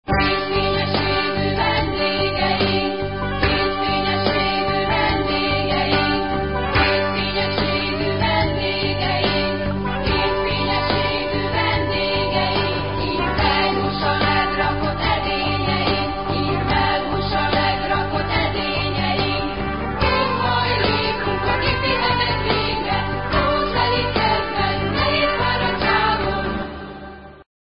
rock-opera - CD 1996.